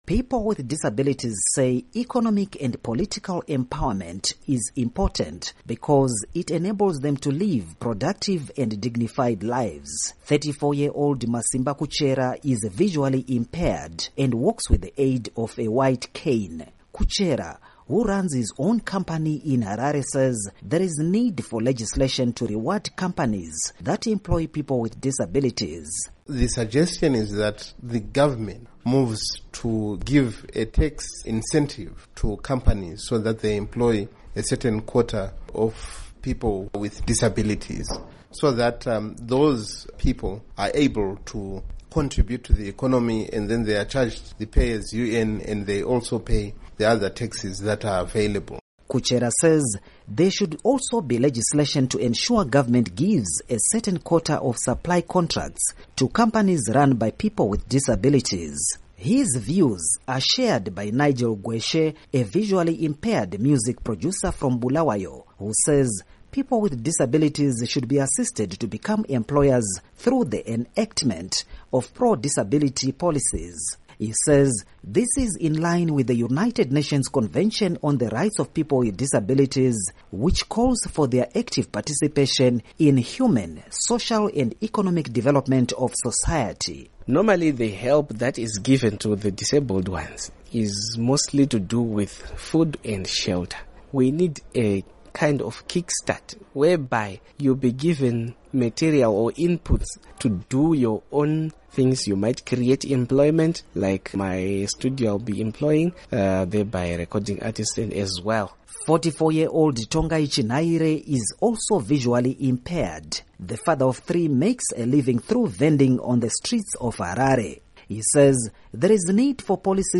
HARARE —